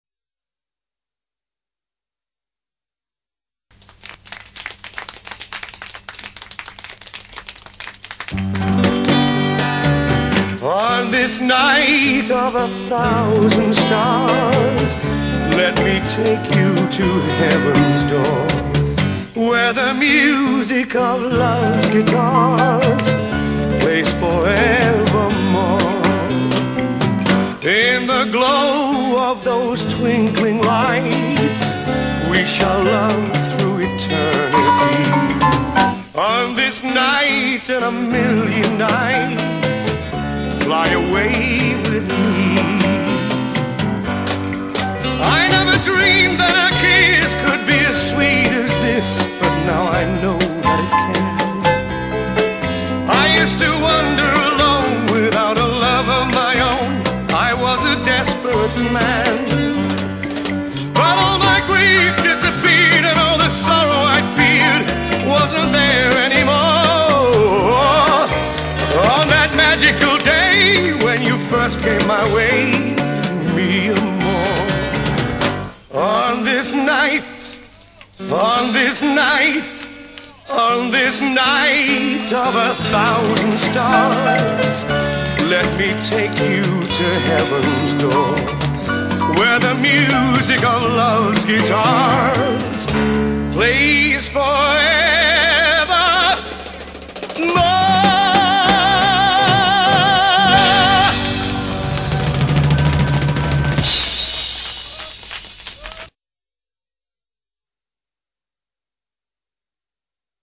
A night club in Junin,
is singing and she asks him